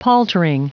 Prononciation du mot paltering en anglais (fichier audio)
Prononciation du mot : paltering